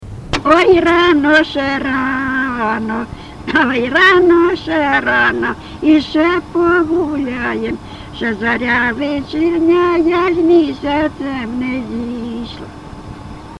ЖанрВесільні
Місце записус. Привілля, Словʼянський (Краматорський) район, Донецька обл., Україна, Слобожанщина